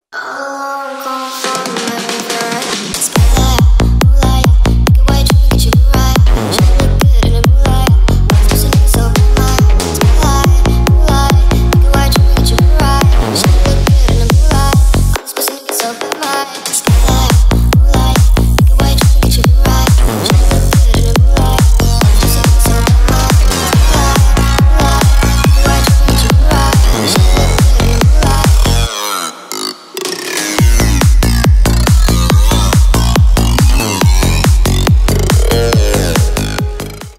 Ремикс
клубные # ритмичные